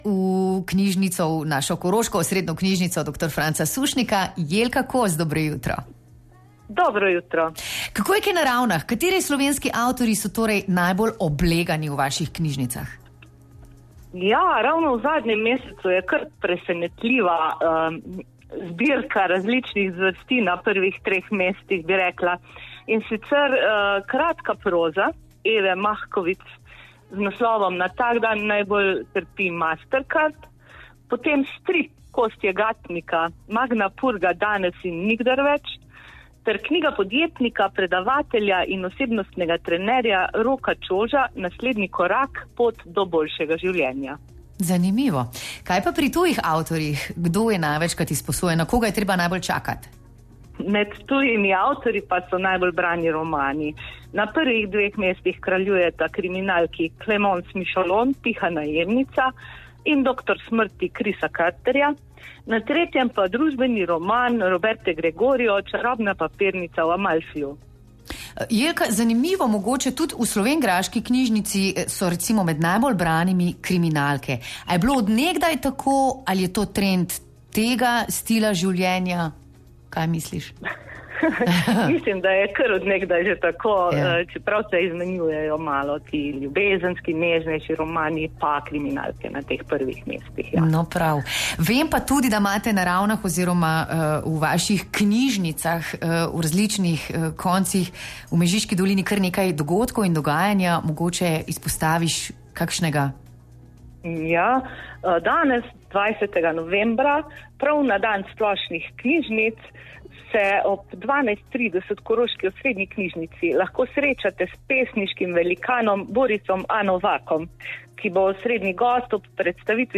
Poklicali smo v dve največji knjižnici na Koroškem.